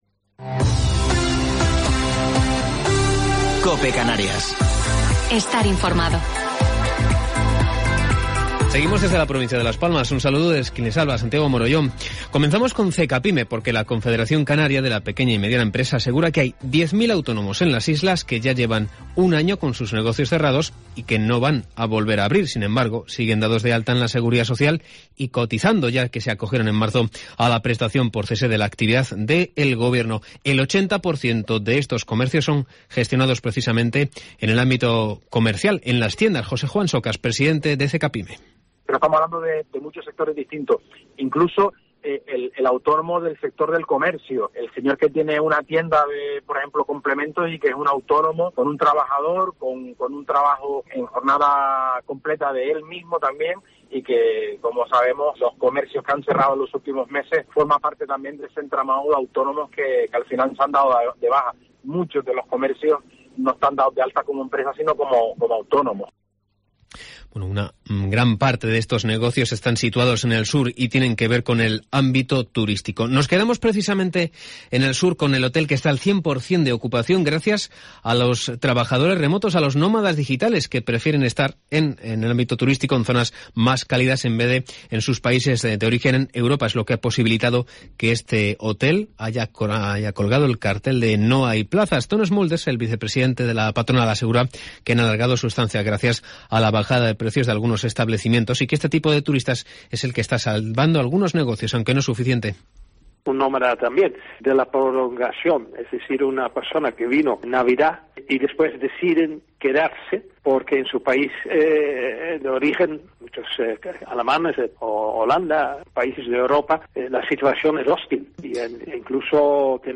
Informativo local 15 de Febrero del 2021